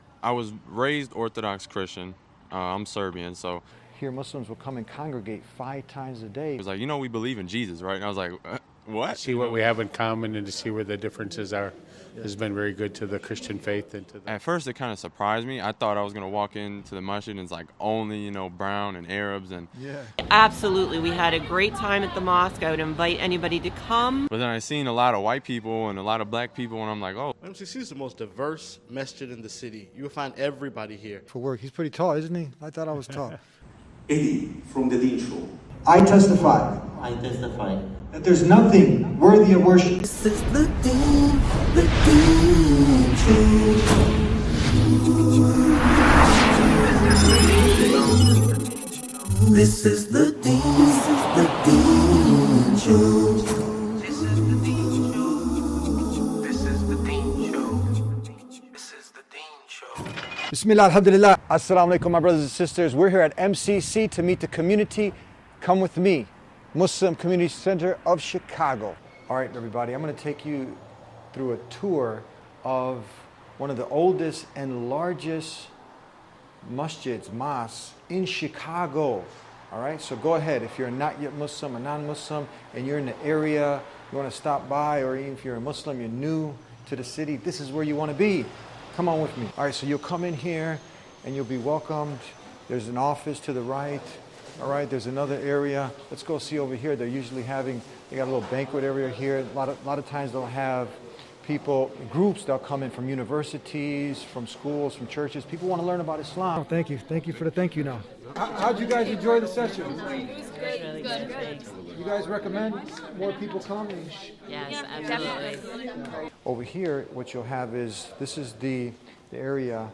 Christians visit Mosque in Chicago what happens next is Amazing!